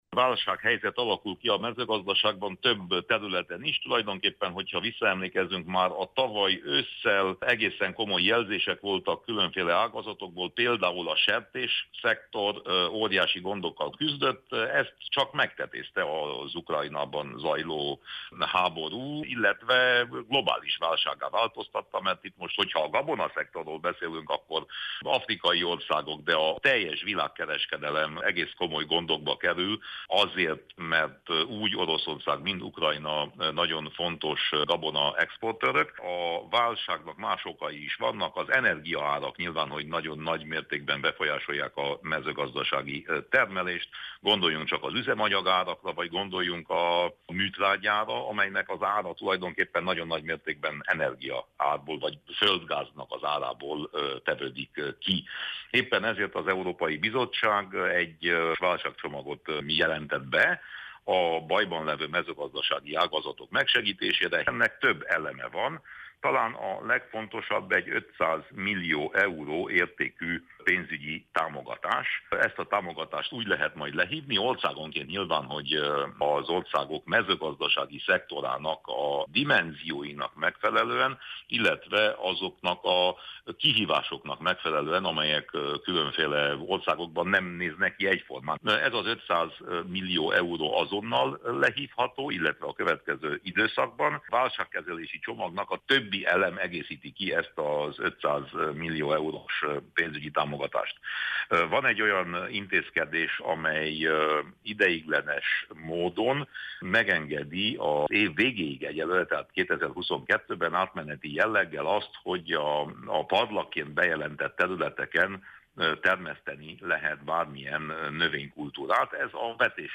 Winkler Gyula EP képviselőt kérdezte